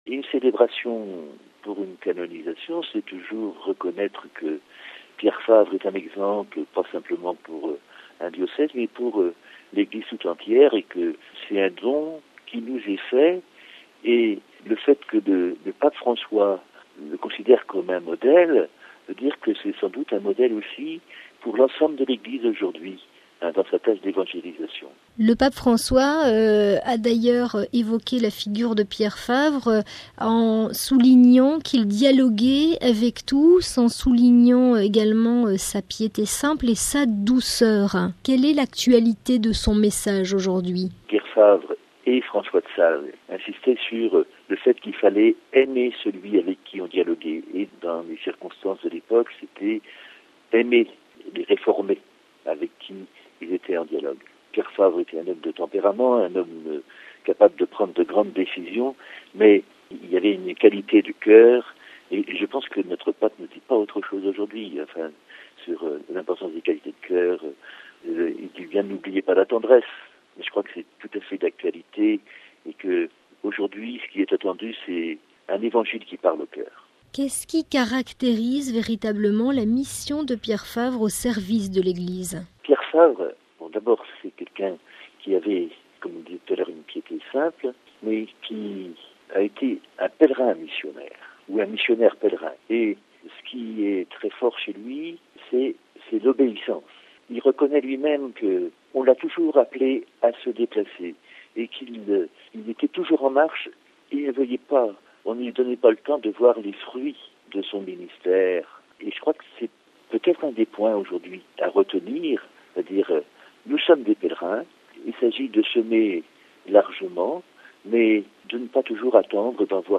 (RV) Entretien - Le Pape François célébrera une messe d’action de grâce pour saint Pierre Favre, cofondateur de la Compagnie de Jésus, vendredi 3 janvier à 9h00 en l'église de la Compagnie de Jésus, à Rome.
Mgr Yves Boivineau, évêque d’Annecy concélébrera la messe d’action de grâce. Il s’attarde sur le sens de cette célébration et sur la signification de la canonisation de ce prêtre jésuite du XVIe siècle qui a parcouru l’Europe.